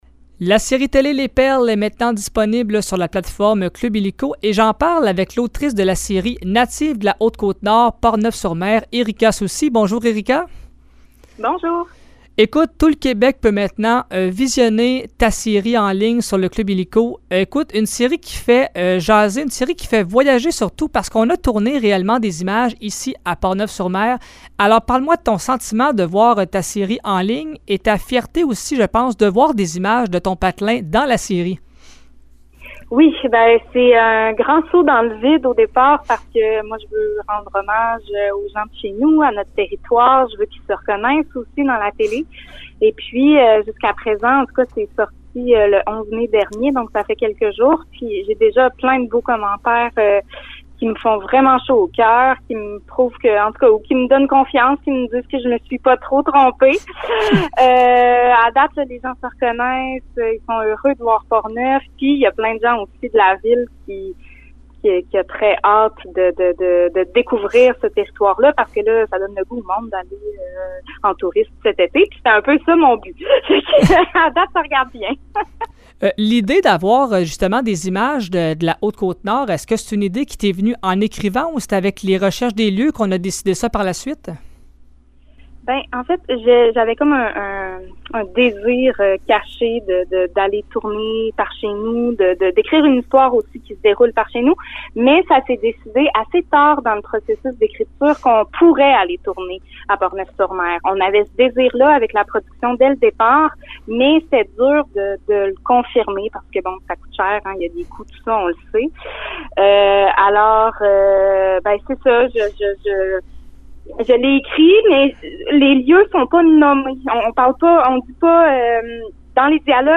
Voici l’entrevue